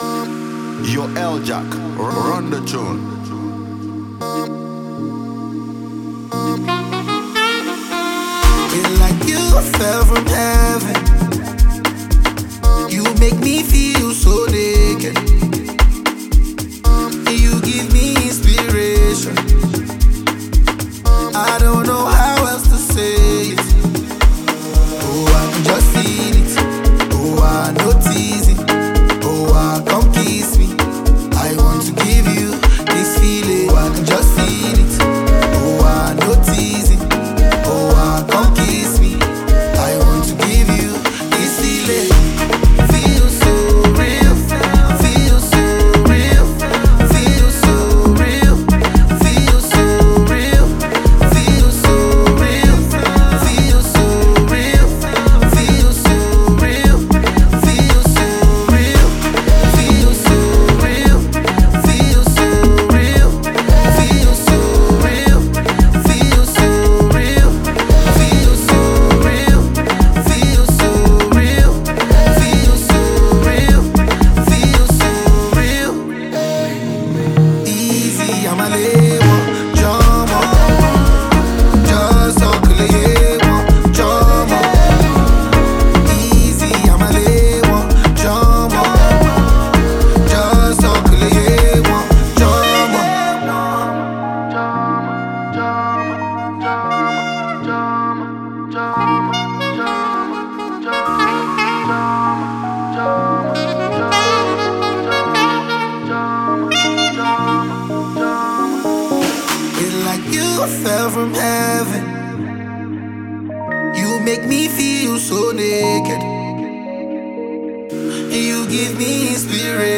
The album mixes Afrobeats with pop and hip-hop sounds.
fun, lively, and full of good vibes